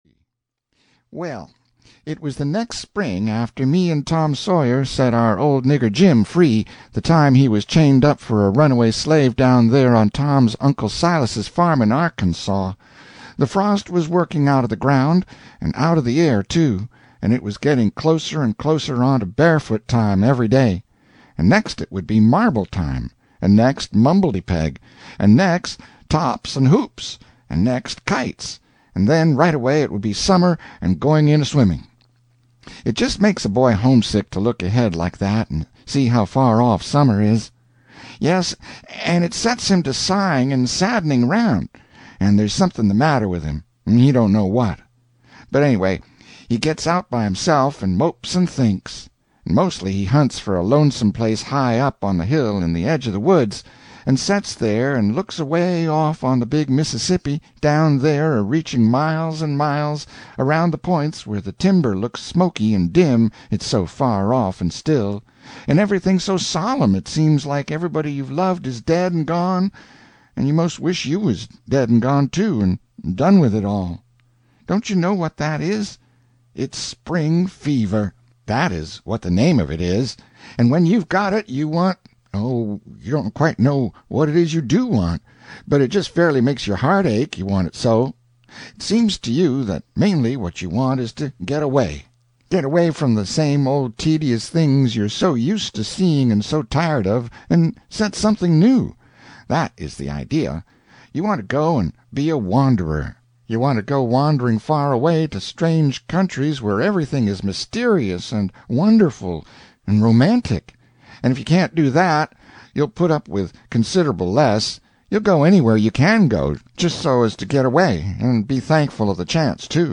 Tom Sawyer, Detective (EN) audiokniha
Ukázka z knihy